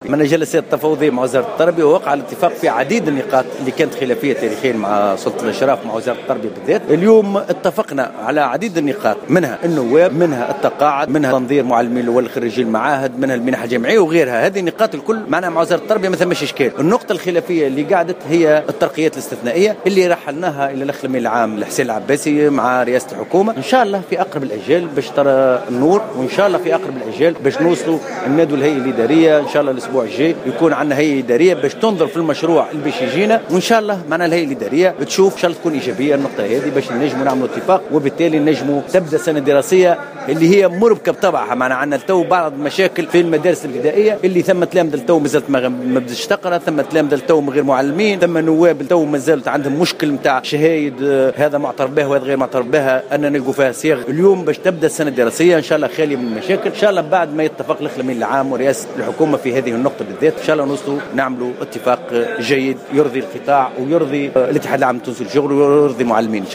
على هامش اجتماع الهيئة الإدارية الوطنية للاتحاد العام التونسي للشغل بمدينة الحمامات